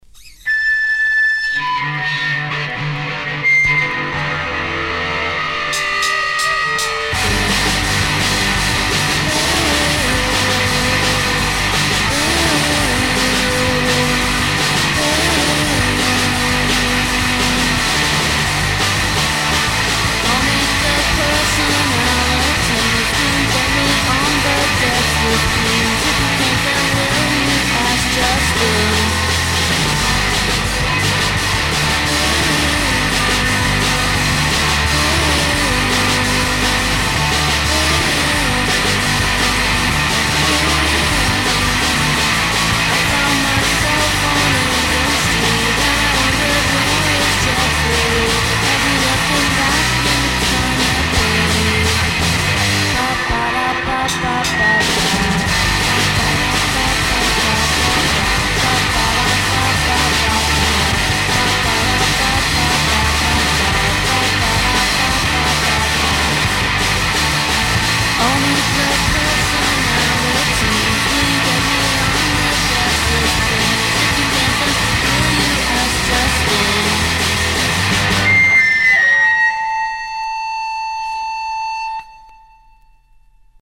Indiepop, indie rock e brindisi!
bella scossa elettrica